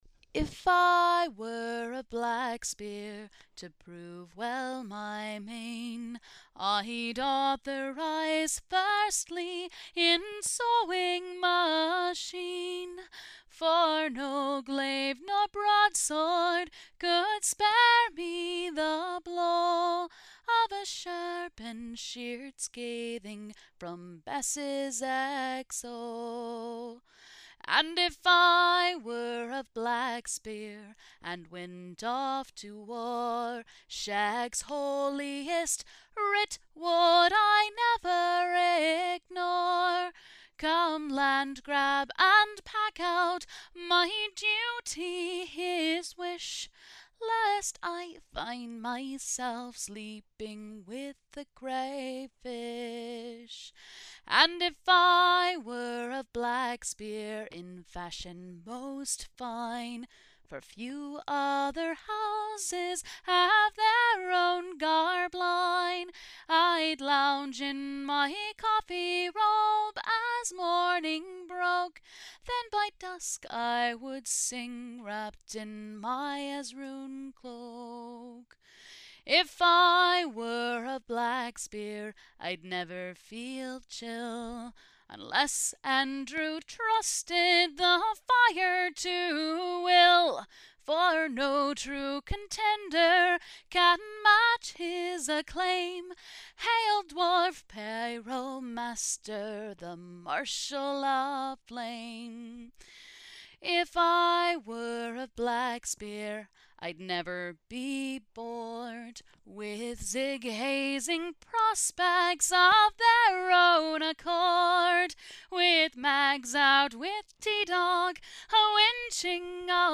Medieval Folk Music for the Current Middle Ages